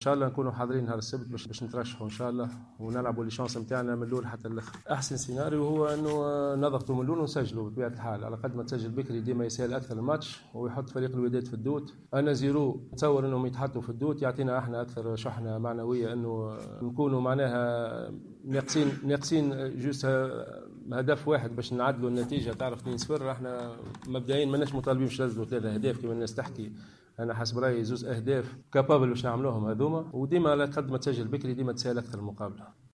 مؤتمرا صحفيا